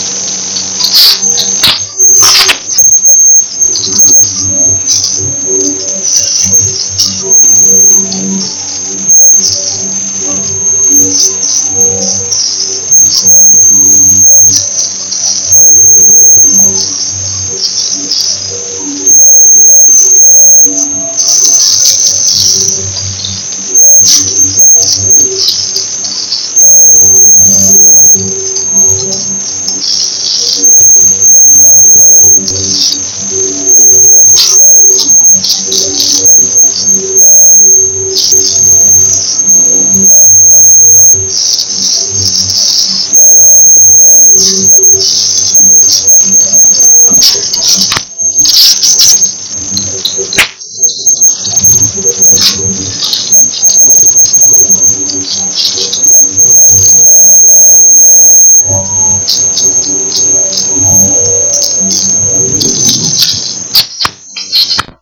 If you use an ultrasound microphone you can sometimes record what is in the ultrasound canopy.  you would not normally hear this if not for the ultrasound microphone which translate it back down to the hearing range – this is how an ultrasound microphone works.  You might hear this in situ only as a modulating tinnitus tone.
This recording is with the microphone close to the computer.
recording ultrasound talking computer 31 oct 2015